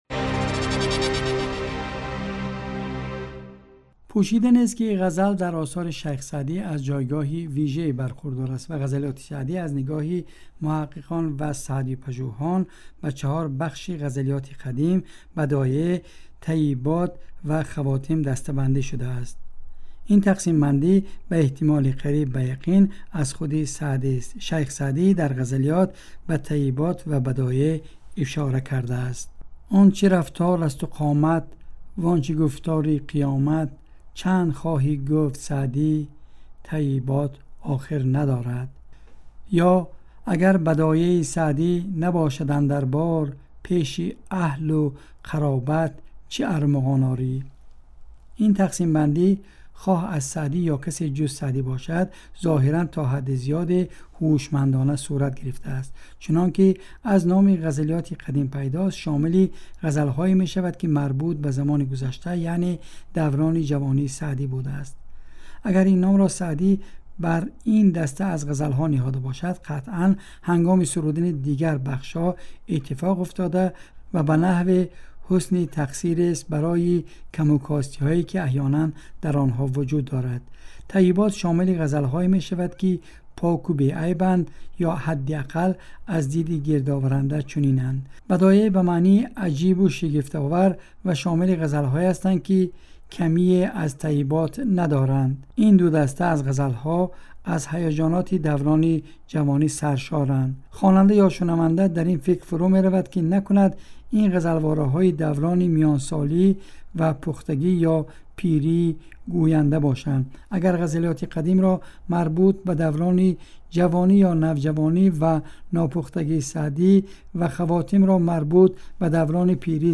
Нигоҳе ба вижагиҳои ғазалҳои Саъдӣ (гузориши вижа)